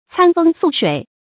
餐風宿水 注音： ㄘㄢ ㄈㄥ ㄙㄨˋ ㄕㄨㄟˇ 讀音讀法： 意思解釋： 形容旅途的辛苦。